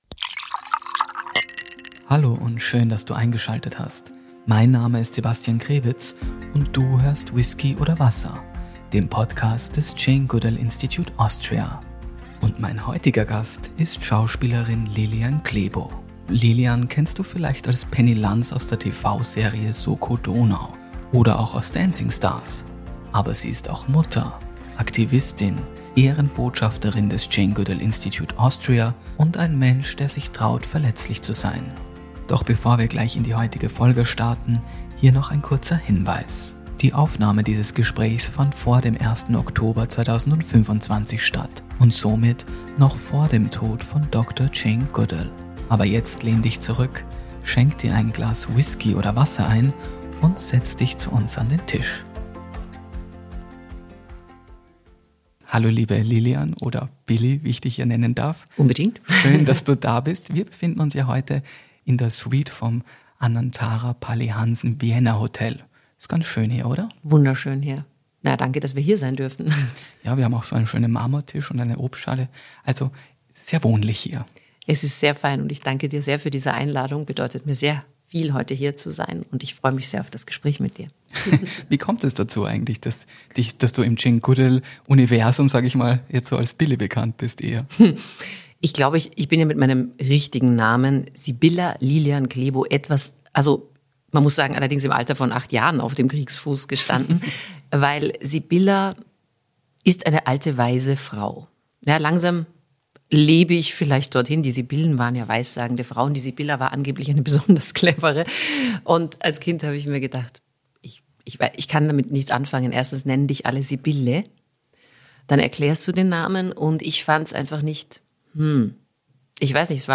Ein Gespräch über Identität und Improvisation, über Sternstunden und Schattenmomente, über Uganda, das Tanzen für sich selbst, Tränen und Träume – und darüber, was bleibt, wenn der Applaus verhallt ist.